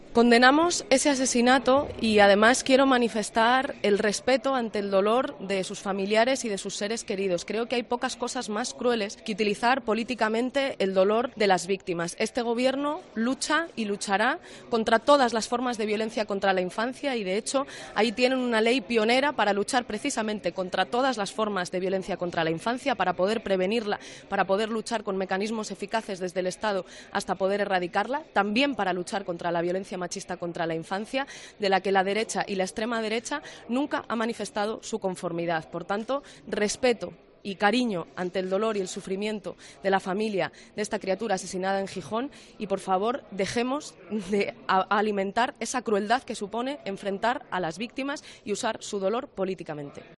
"Condenamos ese asesinato y además quiero manifestar el respeto ante el dolor de sus familiares y de sus seres queridos, creo que hay pocas cosas más crueles que utilizar políticamente el dolor de las víctimas", ha subrayado Montero en los pasillos del Congreso.